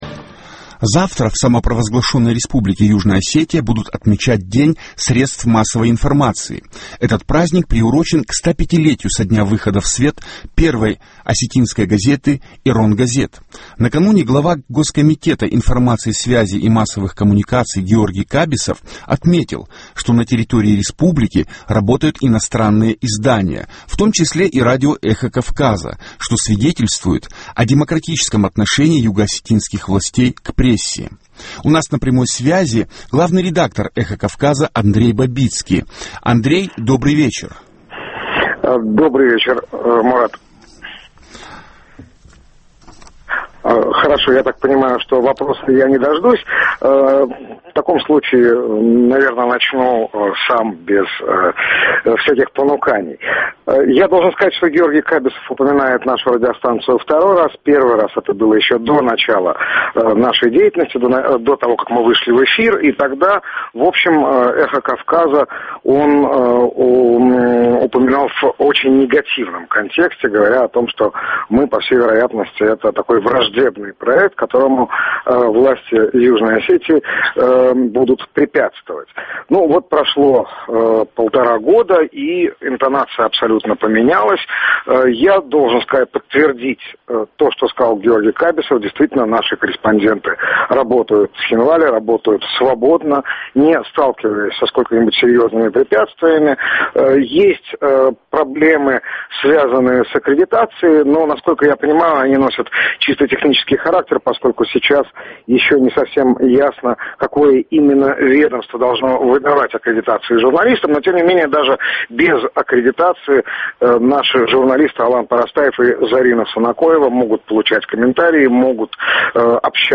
У нас на прямой связи главный редактор «Эхо Кавказа» Андрей Бабицкий.